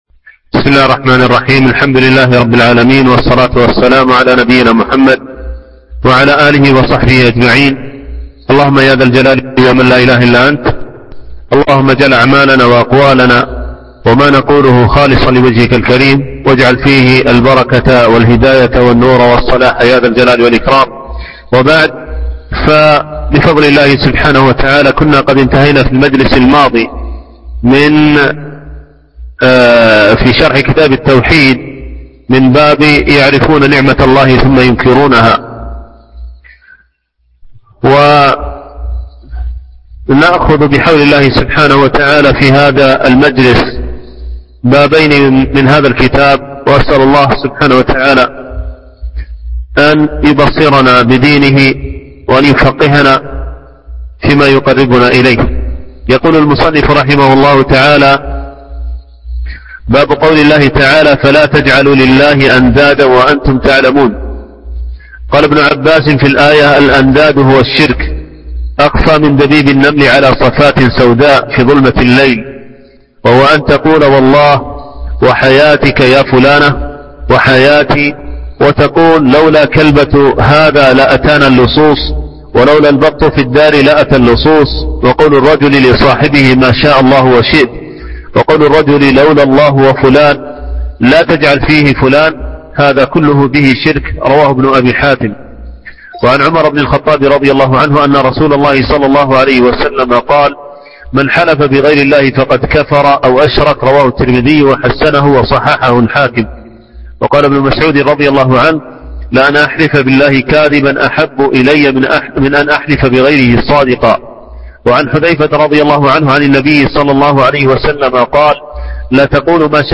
شرح كتاب التوحيد - الدرس السادس والثلاثون